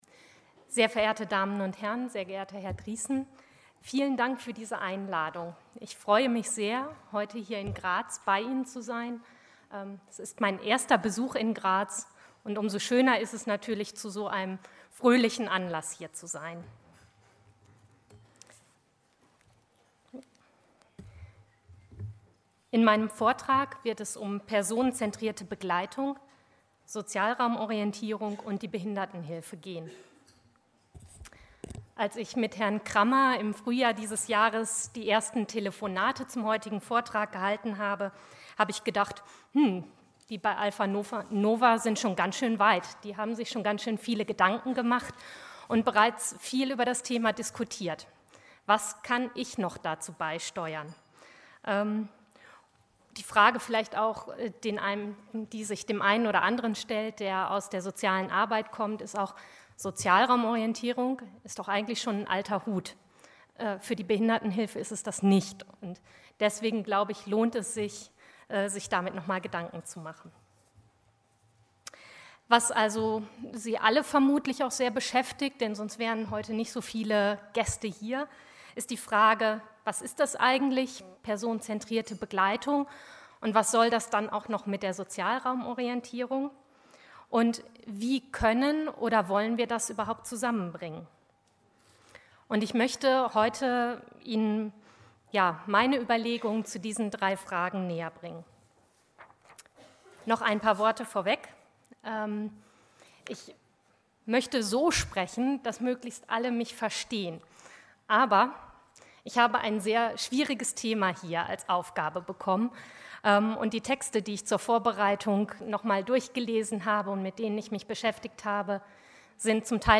Vorträge | Fachtagung Person-zentrierte Begleitung und Sozialraumorientierung – alpha nova